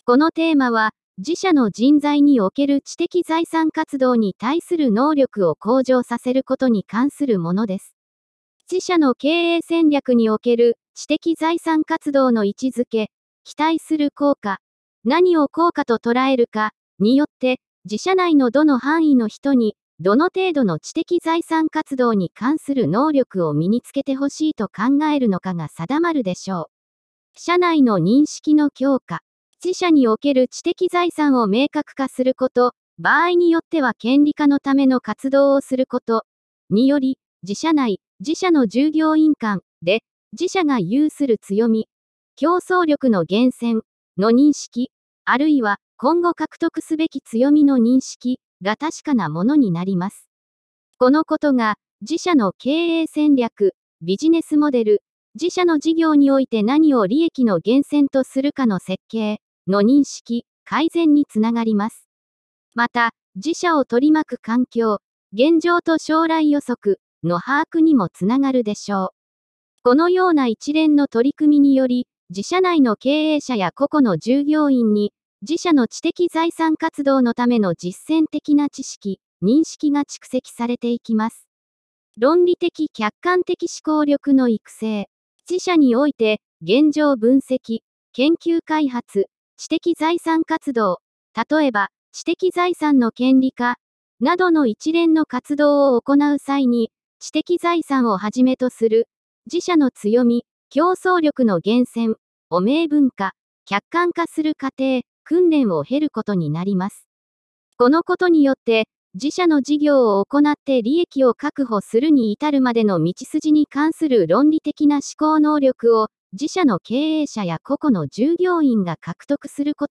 テーマの説明音声データ＞＞（知財系の資格の部分は除く）